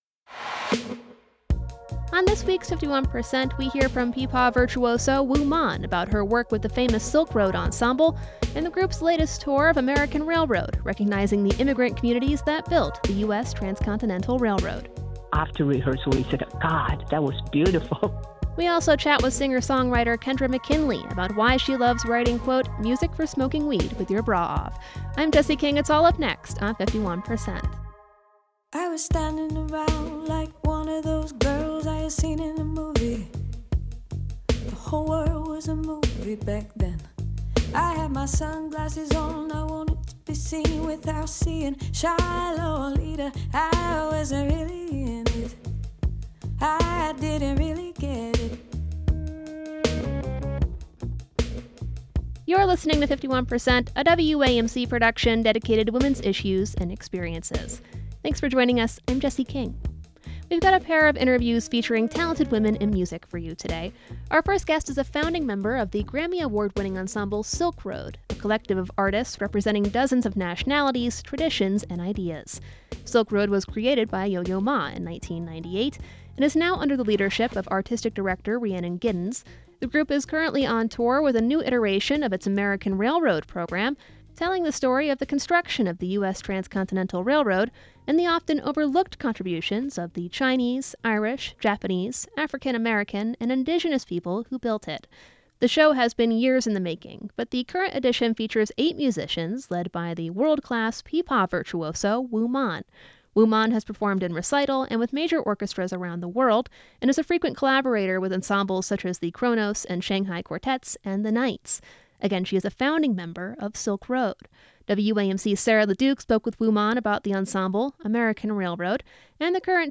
singer-songwriter 51% is a national production of WAMC Northeast Public Radio in Albany, New York.